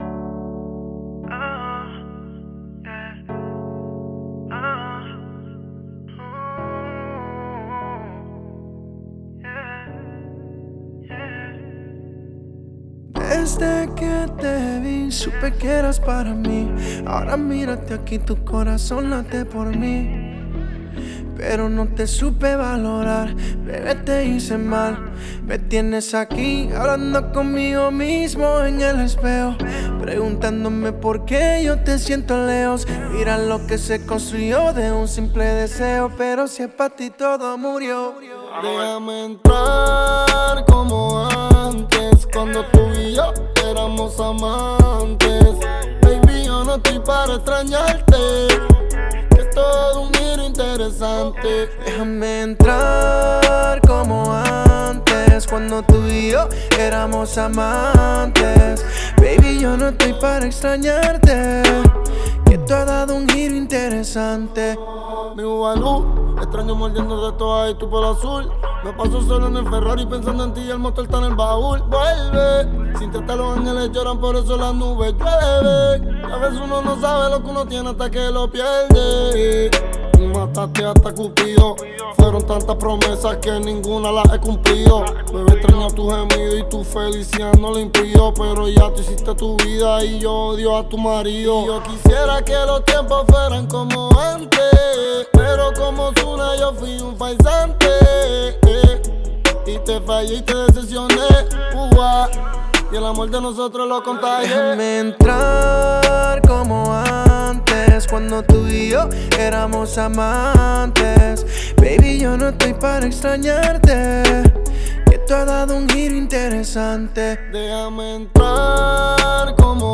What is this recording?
Genre: Punjabi